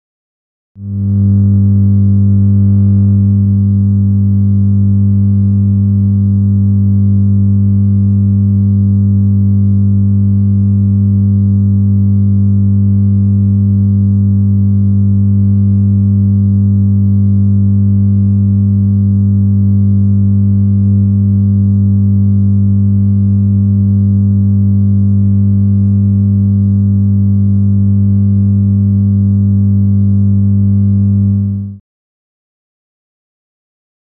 Generator; Heavy Constant Generator / Motor Hum.